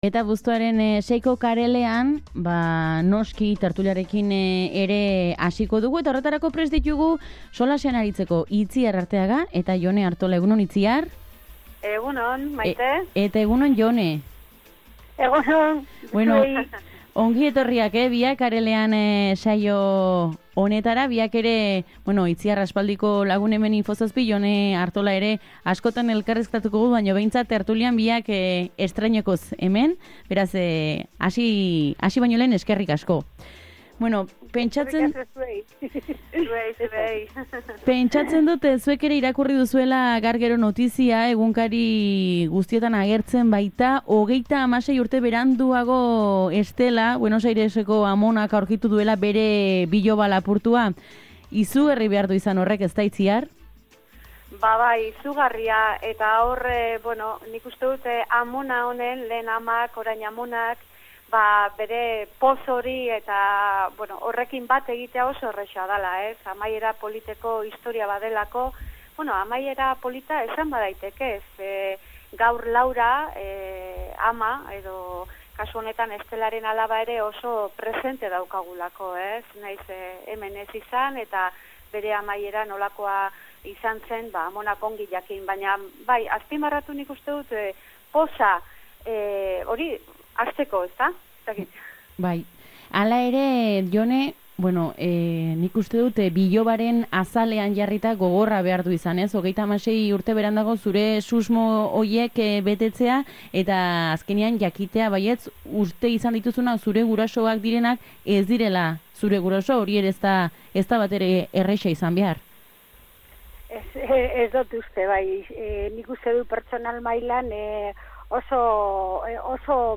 Tertulian: Maiatzako Plazako amonak, parekidetasuna festetan eta Israelgo armadak jasotako Garnier oparia